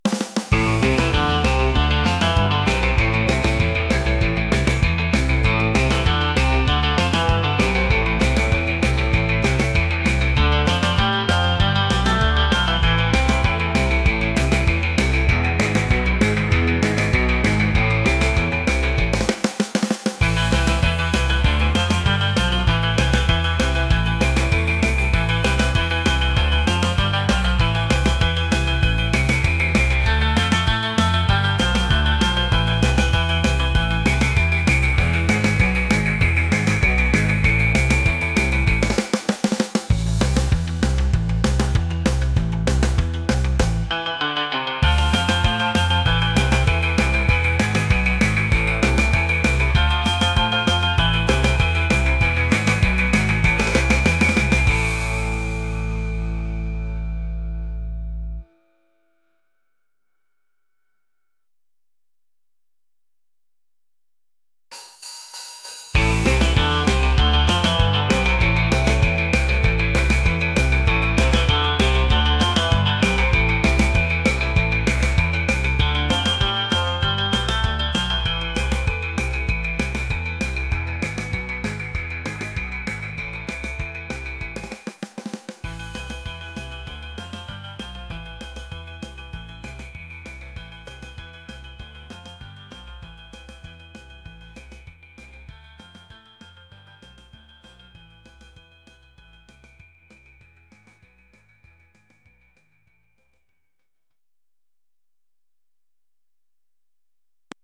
rock | energetic | vibes